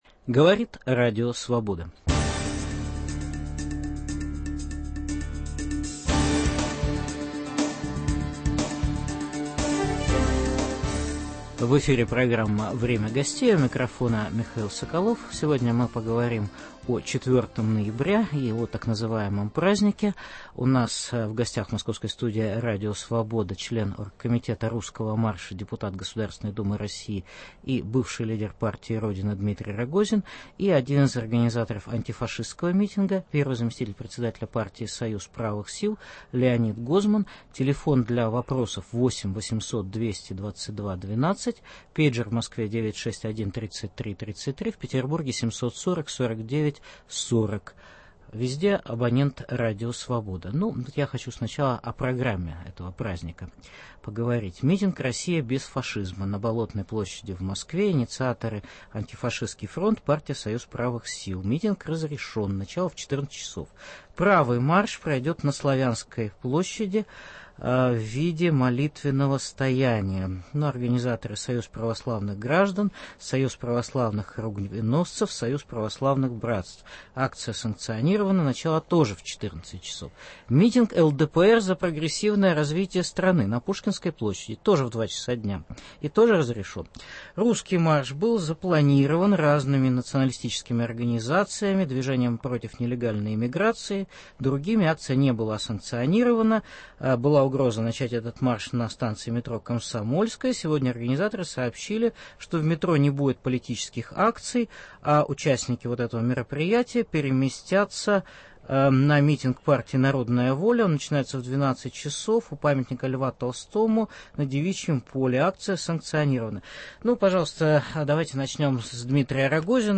День «народного единства» 4 ноября: «Русский марш» против Антифашистского митинга. В программе дискутируют: член Оргкомитета марша бывший лидер партии «Родина» Дмитрий Рогозин и один из организаторов митинга, первый зам председателя партии Союз правых сил Леонид Гозман.